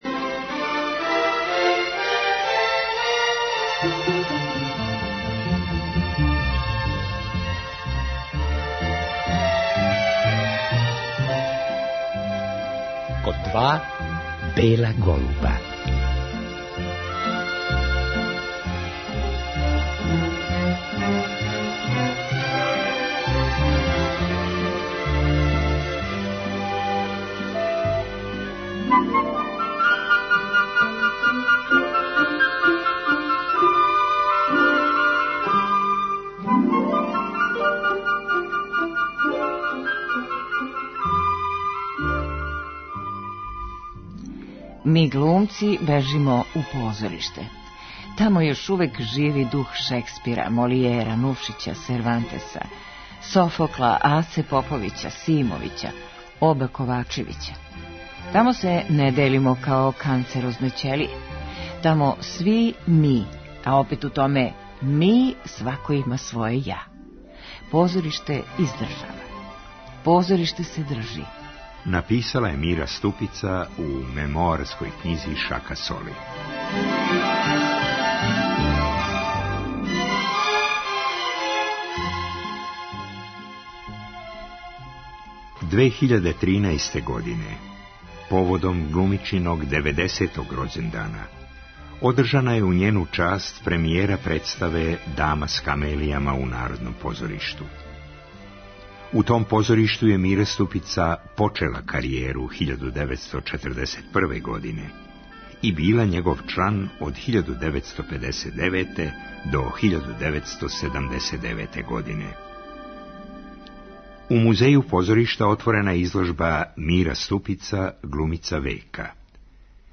Слушаћемо Мирина сећања снимљена за ову емисију 1988. године.